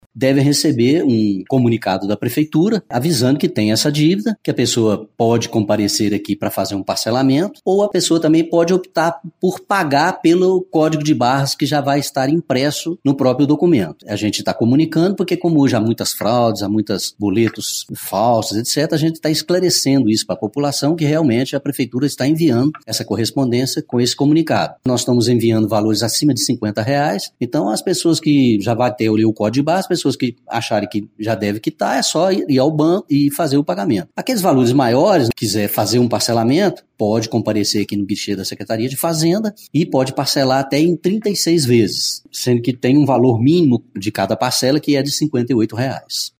O secretário de Gestão Fazendária, José Leonardo Martins Pinto, traz detalhes do comunicado. Ressalta que o procedimento facilita o pagamento das dívidas, evitando que os contribuintes sejam vítimas de golpes.